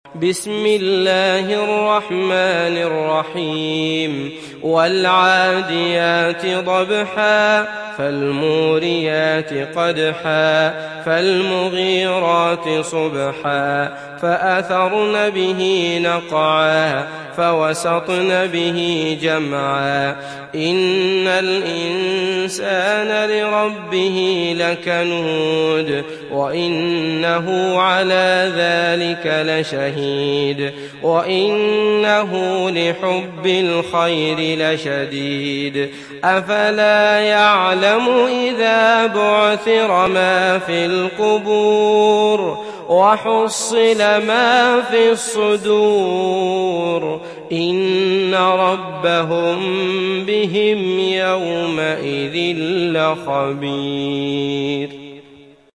Adiat Suresi mp3 İndir Abdullah Al Matrood (Riwayat Hafs)
Adiat Suresi İndir mp3 Abdullah Al Matrood Riwayat Hafs an Asim, Kurani indirin ve mp3 tam doğrudan bağlantılar dinle